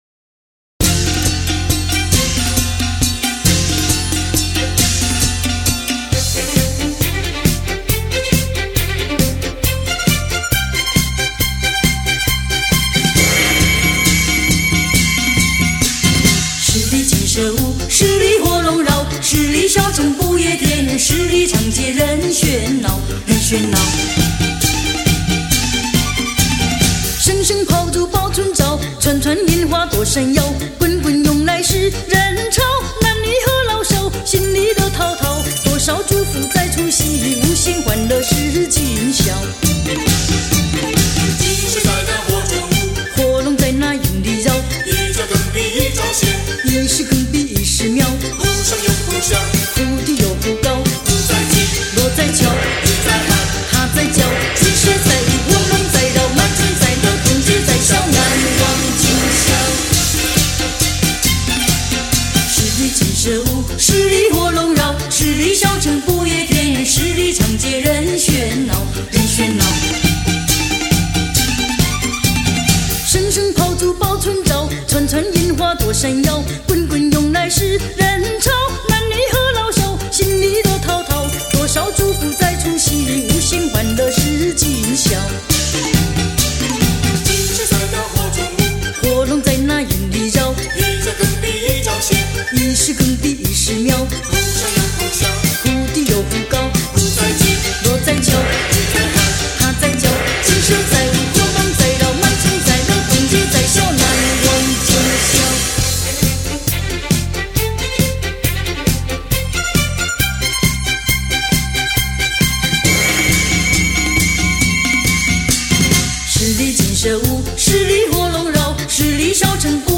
情感细腻 值得回味